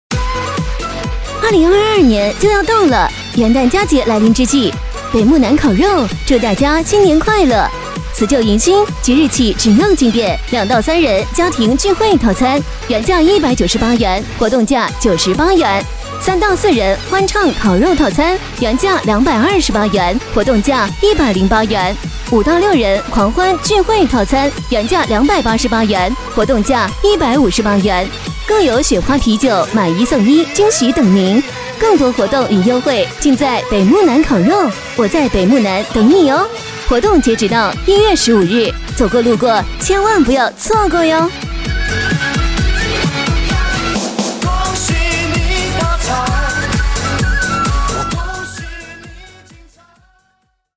国语配音
女107--促销-餐饮-贺新年.mp3